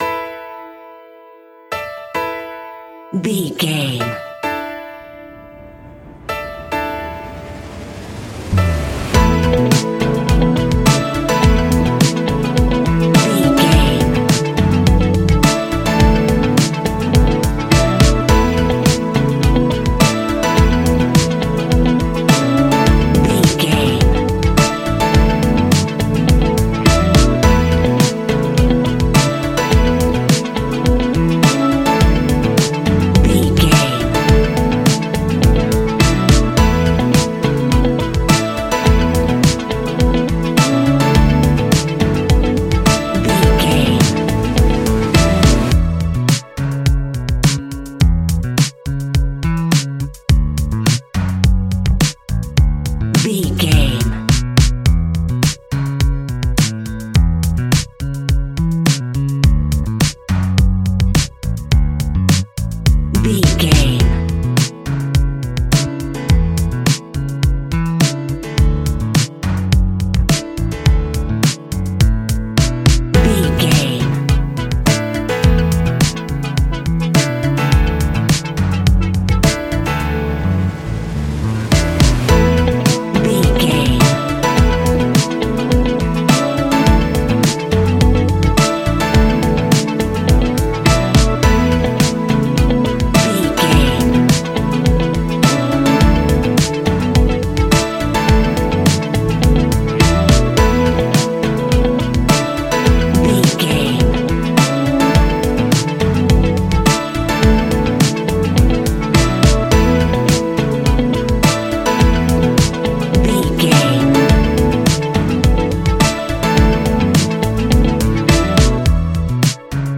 Ionian/Major
ambient
electronic
new age
downtempo
pads
drone